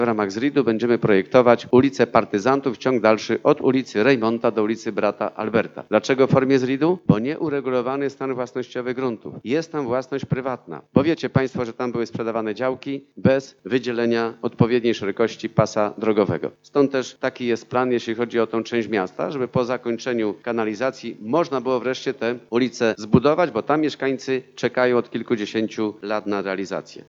W magistracie trwają prace związane z dobudową tej drogi aż do skrzyżowania z ulicą Świętego Brata Alberta. Mówi burmistrz Jan Zuba: